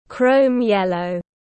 Chrome yellow /ˌkrəʊm ˈjel.əʊ/